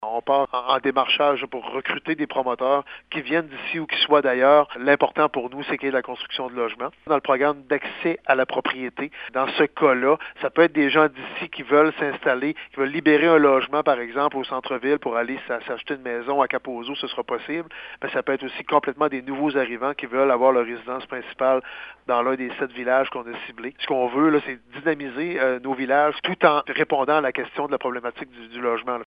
Daniel Côté espère que les promoteurs profiteront de ces programmes d’accès aux logements qui visent à contrer la pénurie qui sévit actuellement sur notre territoire :